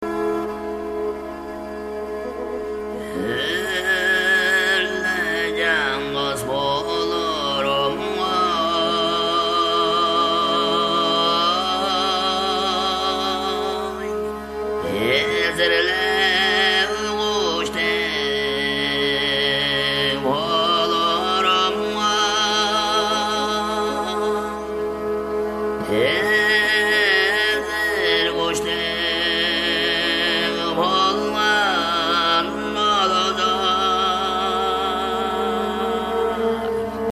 Here is a recording in a mystery language and in an unusual style.
That’s throat-singing, though in this example the singer only harmonicizes for a few brief seconds.